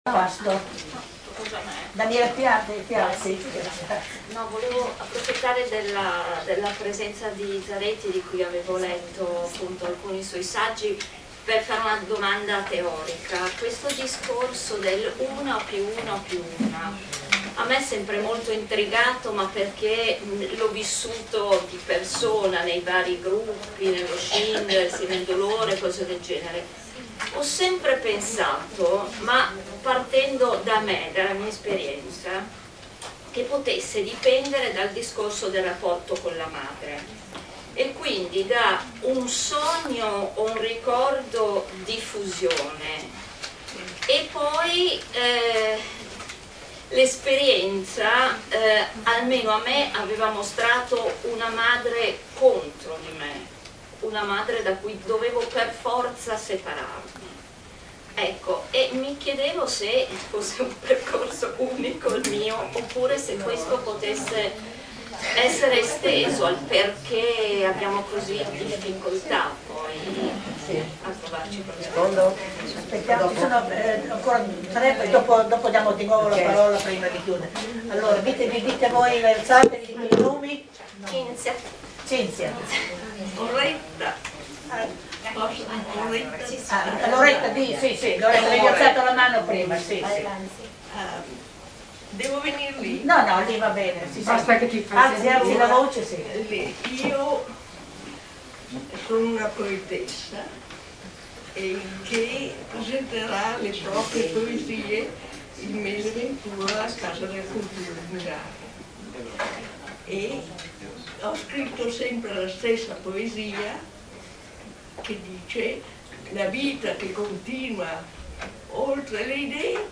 Registrazioni audio del Seminario IL MOVIMENTO DELLE DONNE TRA ACCOMUNAMENTO E FRAMMENTAZIONE Sull'incontro nazionale del femminismo a Paestum